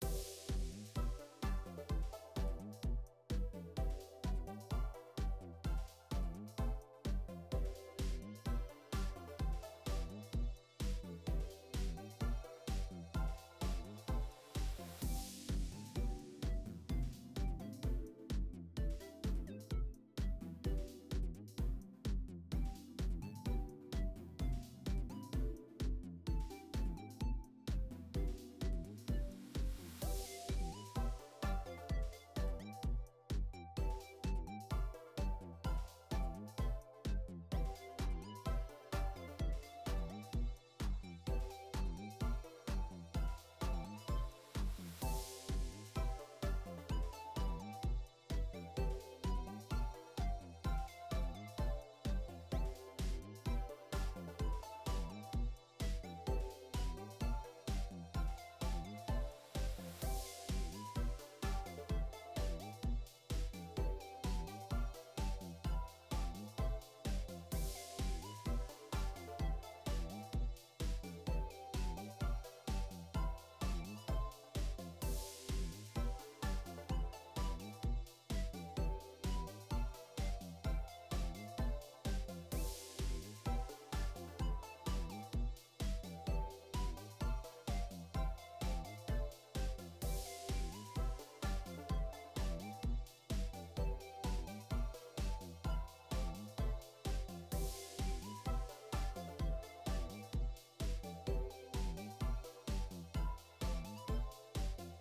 sounds like a main menu theme, pretty good so far!